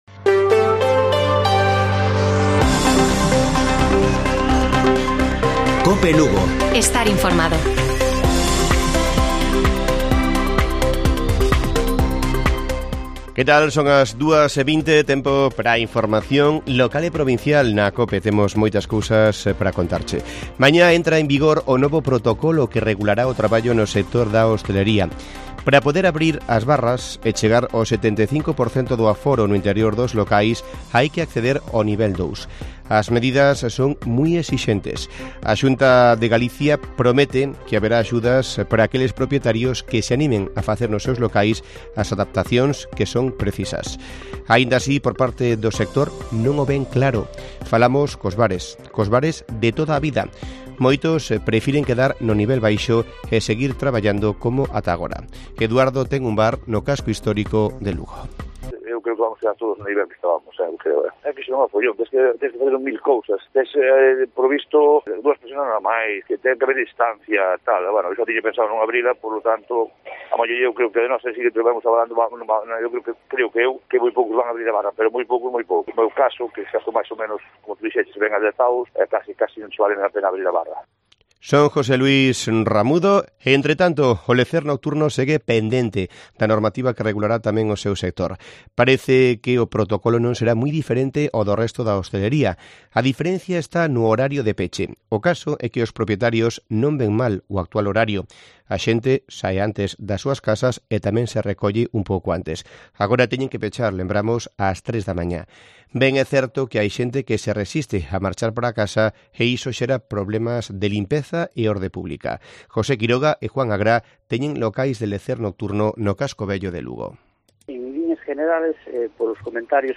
Informativo Mediodía de Cope Lugo. 14 de septiembre. 14:20 horas